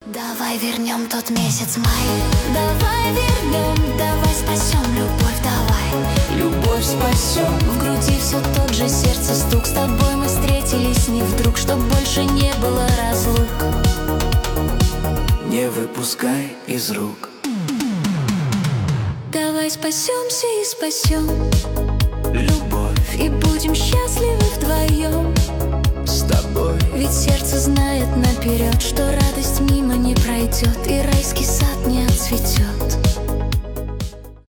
поп , дуэт